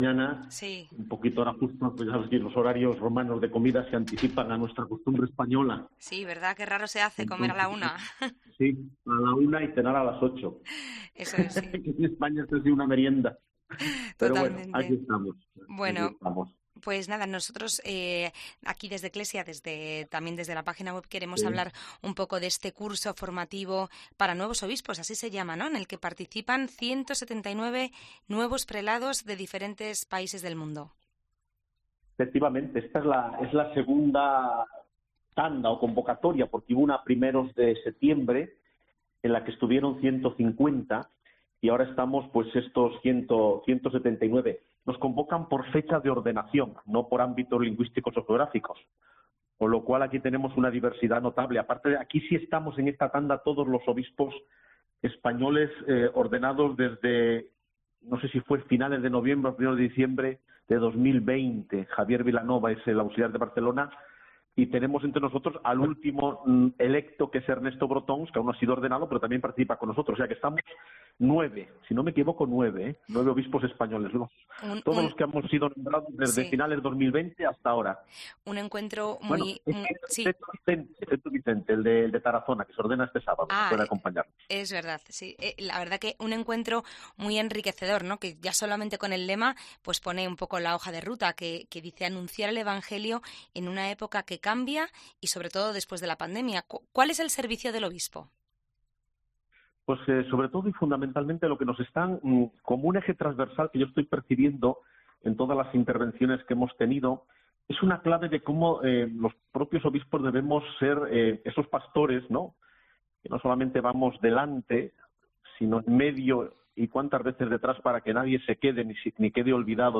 ECCLESIA ha podido hablar con uno de ellos, Francisco José Prieto, obispo auxiliar de Santiago, que nos ha contado cómo en estos encuentros se profundiza en los aspectos más importantes del servicio episcopal, en el contexto del camino eclesial haca el sínodo de 2023.